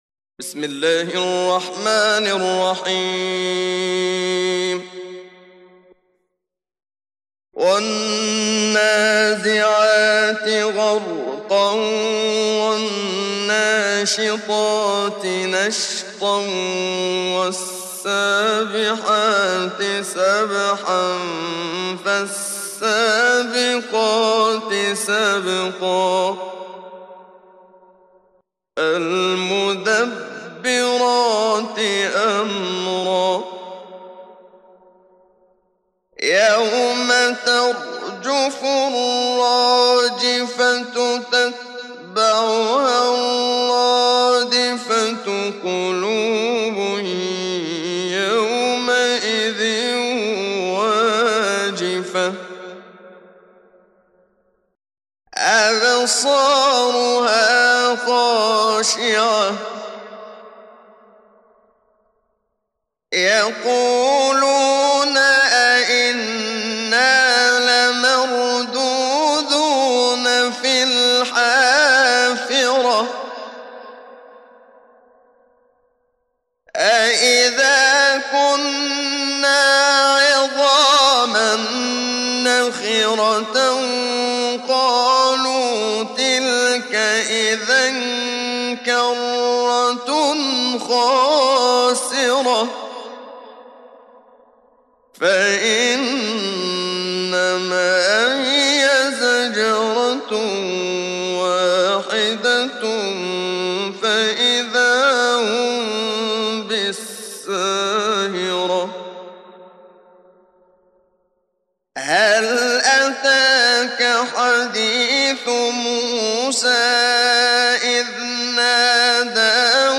Download Surat An Naziat Muhammad Siddiq Minshawi Mujawwad